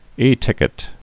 (ētĭkĭt)